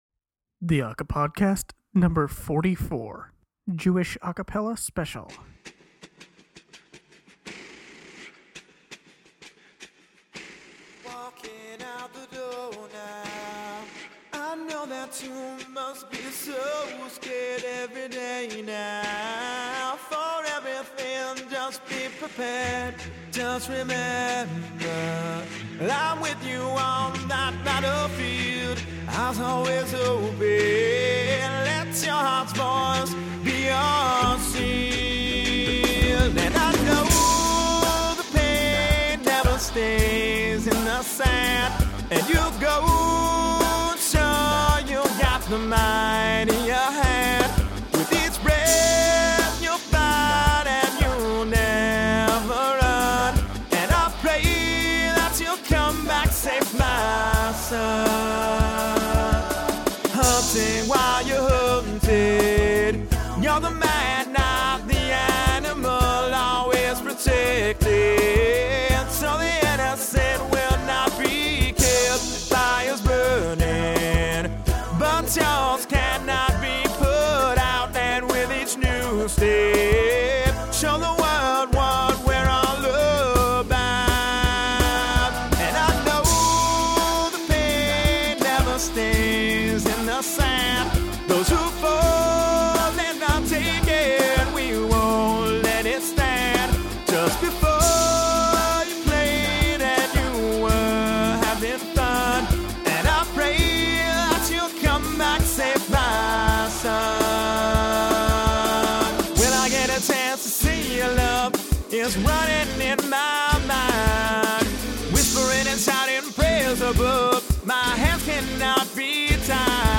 It’s a Jewish A Cappella special episode.